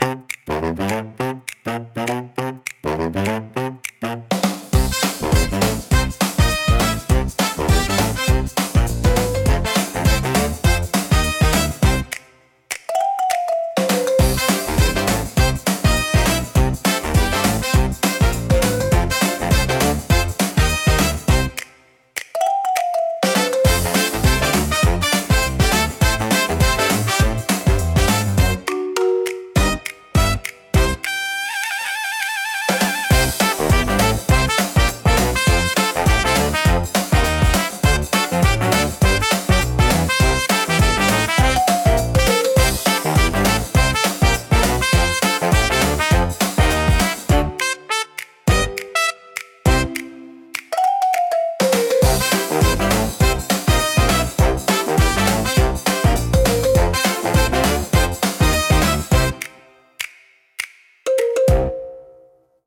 聴く人に軽やかで楽しい気分を届け、明るく気楽な空気を作り出します。